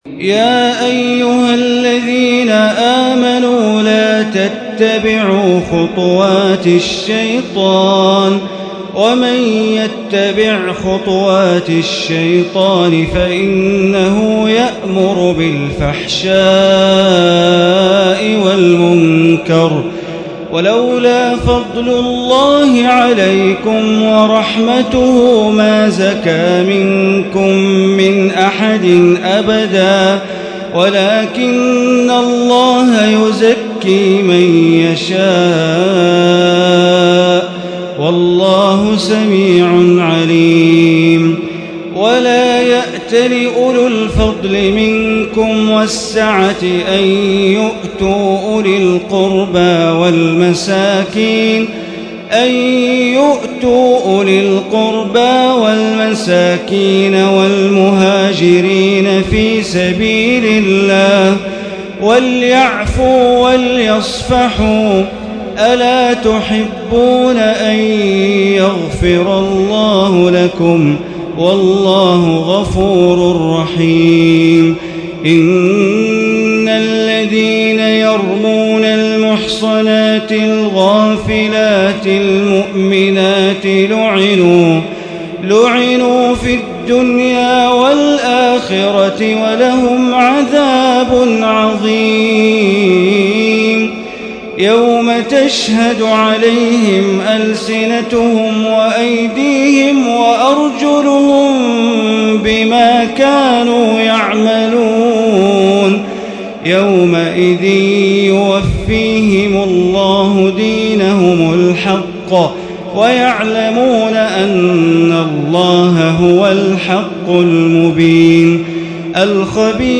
ليلة 17 من رمضان عام 1436 من سورة النور آية 21 إلى سورة الفرقان آية 20 > تراويح ١٤٣٦ هـ > التراويح - تلاوات بندر بليلة